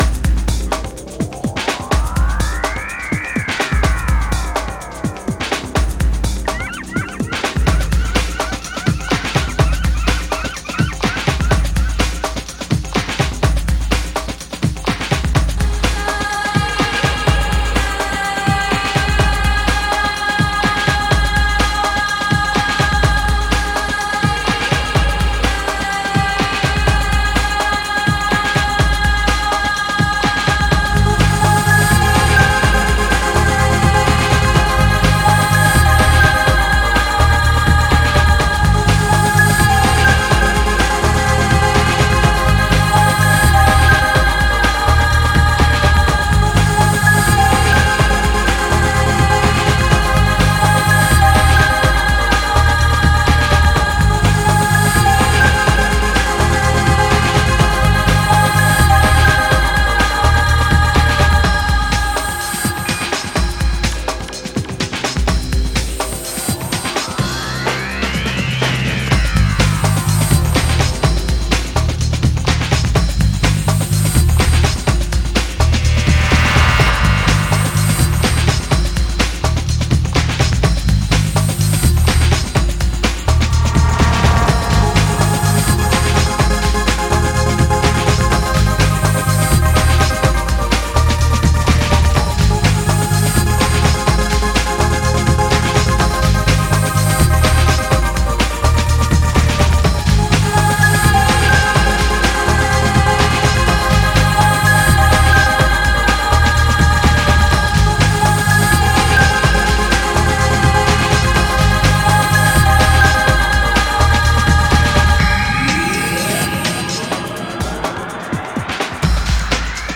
an electronic dance record that’s synthetic yet soulful.